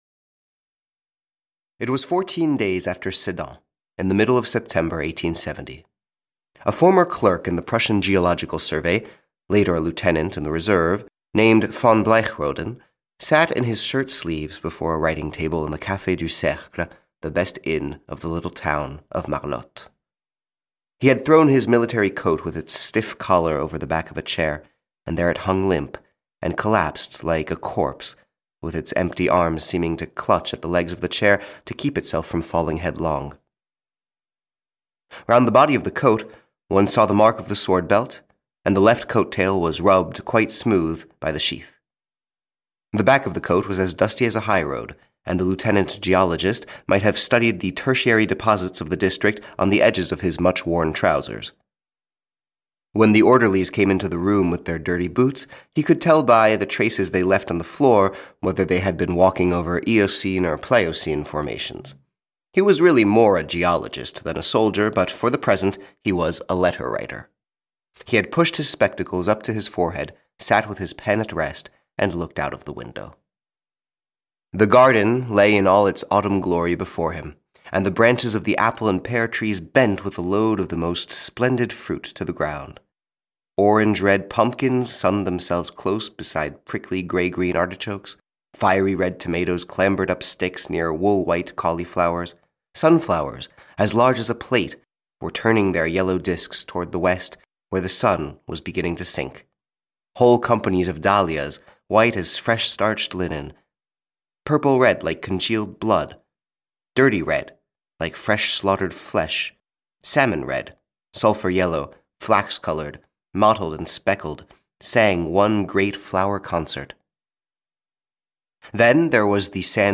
The German lieutenant and other stories (EN) audiokniha
Ukázka z knihy